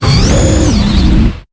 Cri de Dolman dans Pokémon Épée et Bouclier.